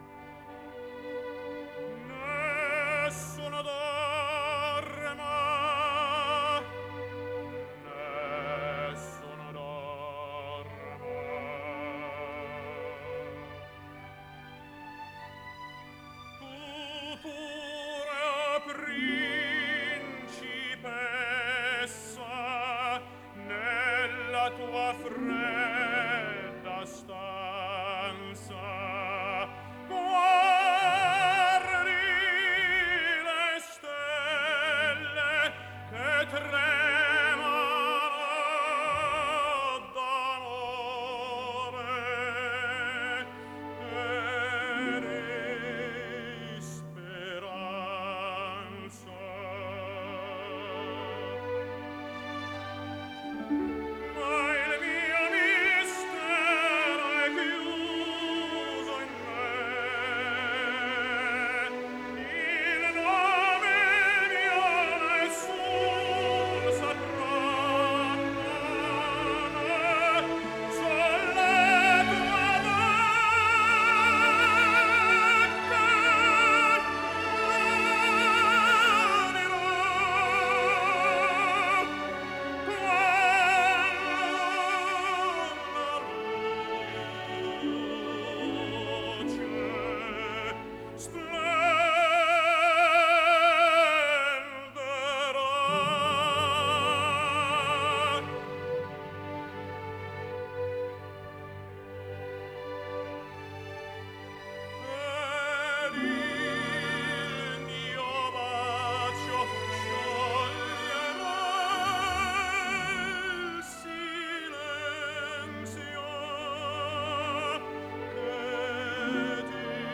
Bass Vocals
Soprano Vocals
Tenor Vocals
Baritone Vocals